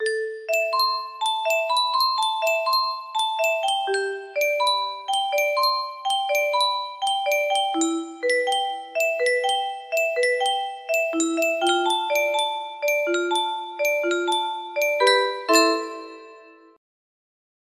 Guitar Chords 1 music box melody